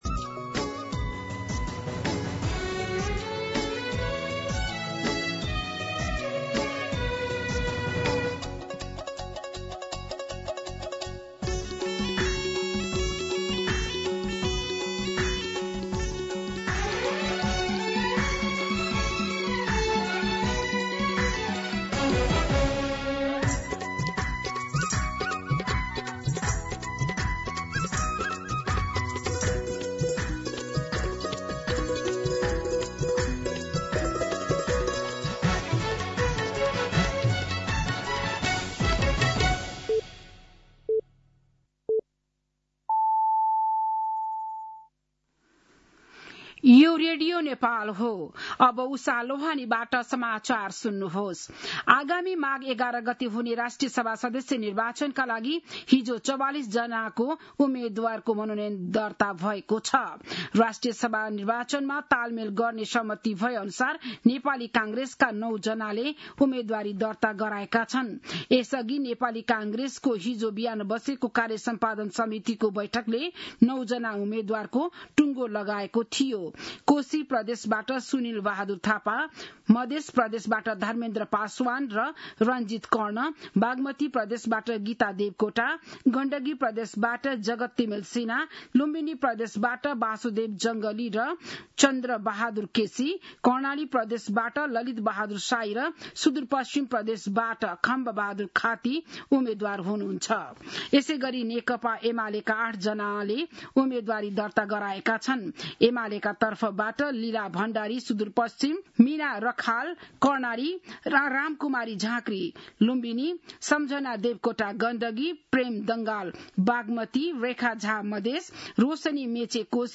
बिहान ११ बजेको नेपाली समाचार : २४ पुष , २०८२